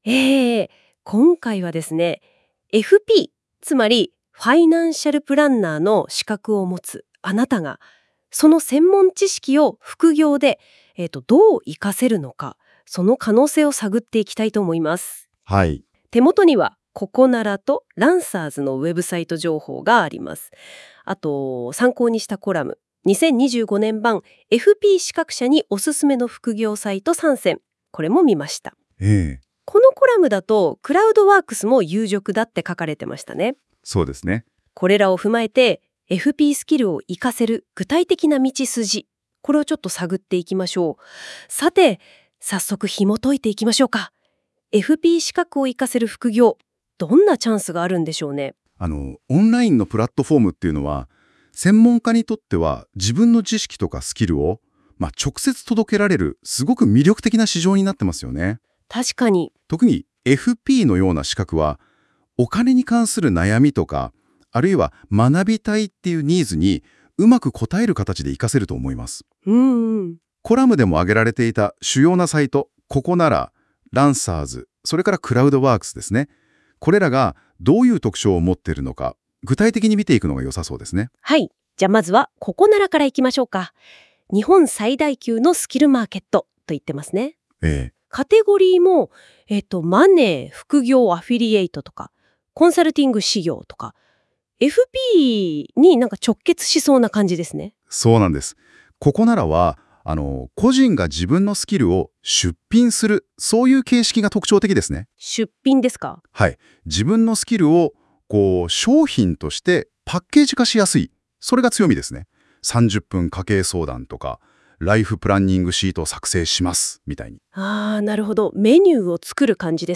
今回のコラムを音声解説を追加！
今回のコラムの理解の促進や、FP副業をより前向きに検討するための音声解説を入れました。